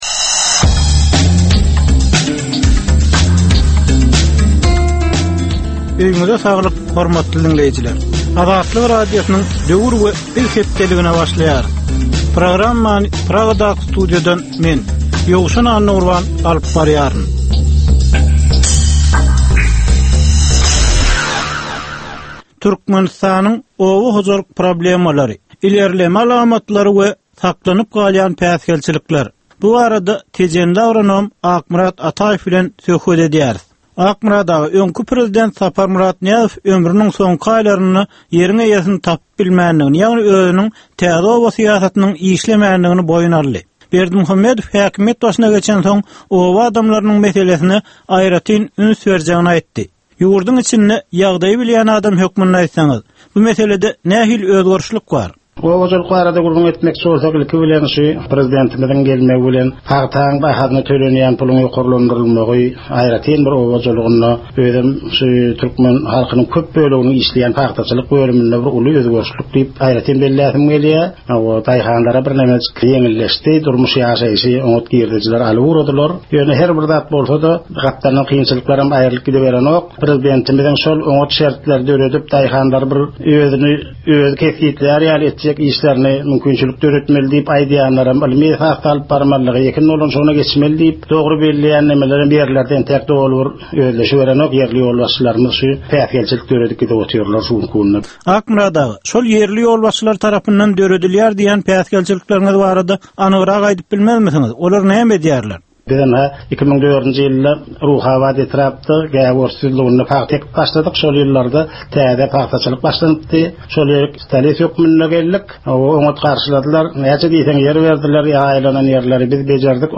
Türkmen jemgyýetindäki döwrüň meseleleri we döwrüň anyk bir meselesi barada ýörite gepleşik. Bu gepleşikde diňleýjiler, synçylar we bilermenler döwrüň anyk bir meselesi barada pikir öwürýärler, öz garaýyşlaryny we tekliplerini orta atýarlar.